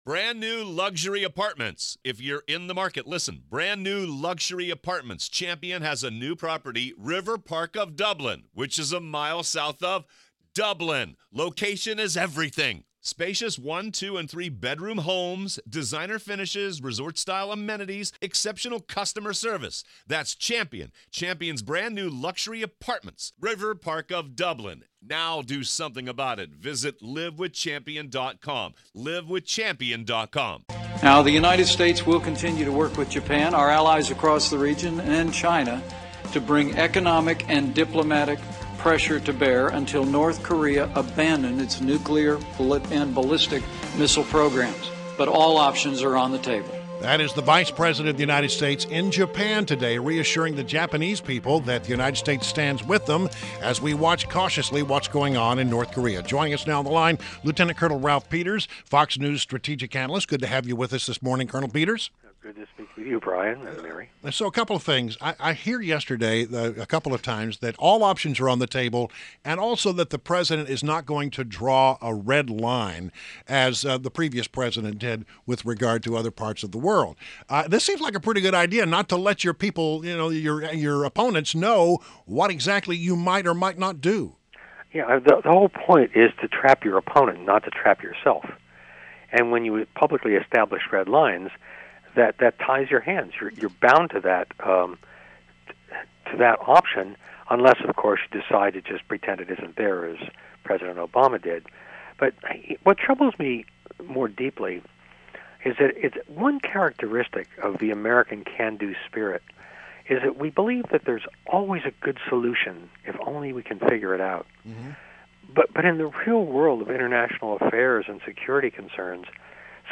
WMAL Interview - LT. COL. RALPH PETERS - 04.18.17